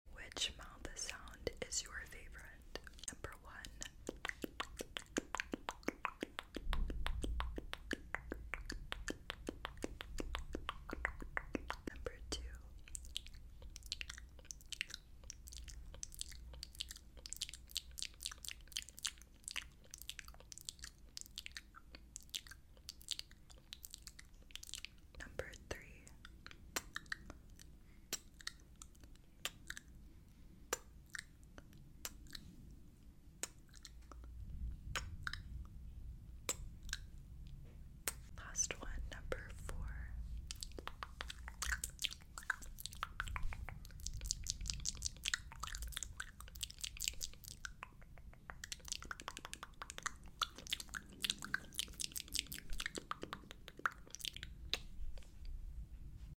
Which Mouth Sound Do You Sound Effects Free Download